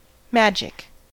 magic-us.mp3